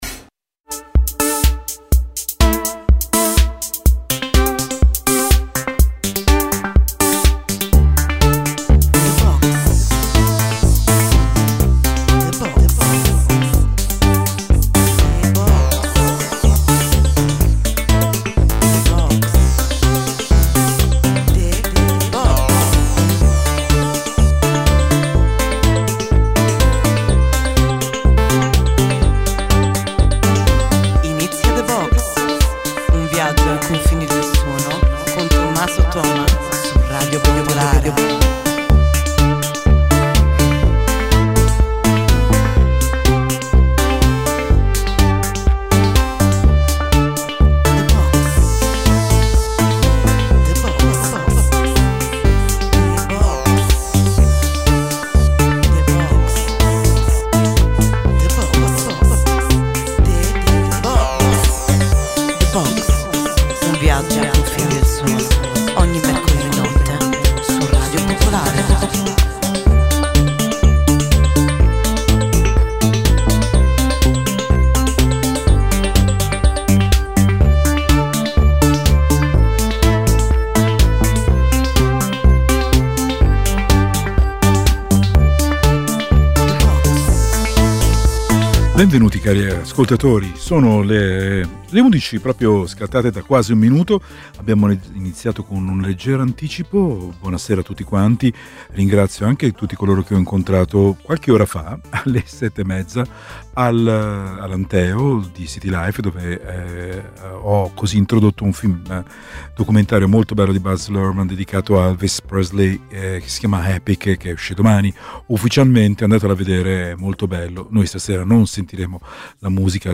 La sigla è un vero e proprio viaggio nel cuore pulsante della notte.